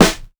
• Good Acoustic Snare Sound D# Key 52.wav
Royality free snare drum sample tuned to the D# note. Loudest frequency: 1885Hz
good-acoustic-snare-sound-d-sharp-key-52-poY.wav